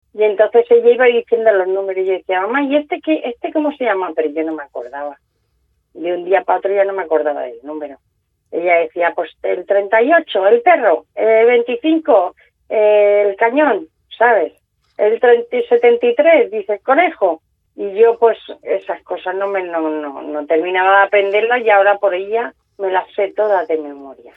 con especial sentir formato MP3 audio(0,46 MB), como si de un entrañable homenaje se tratara.